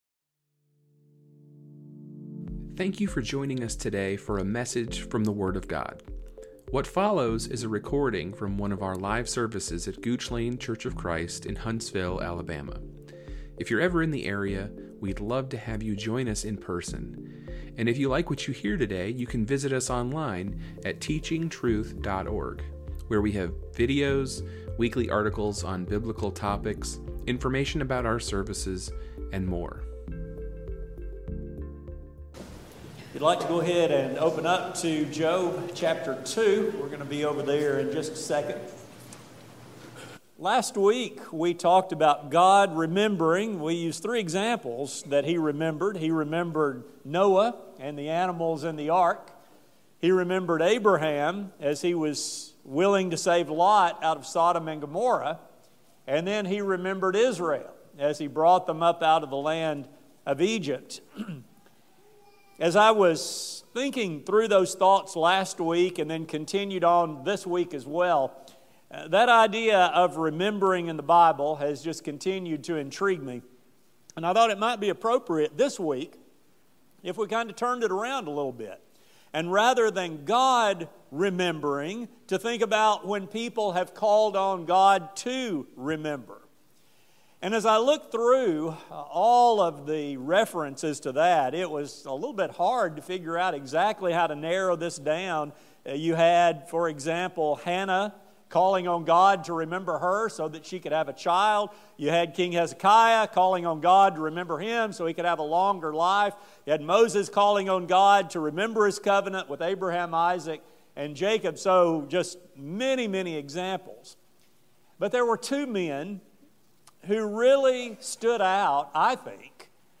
Gooch Lane Church of Christ Podcast